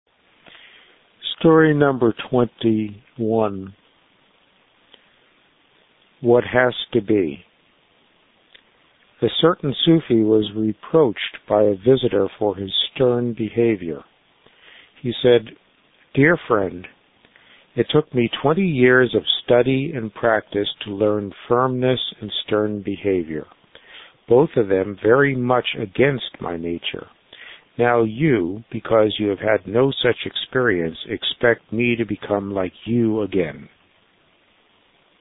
Note: each of the following begins with a reading of the story and then the commentary.